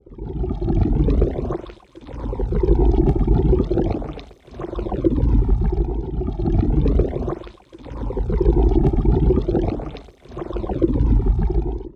water.wav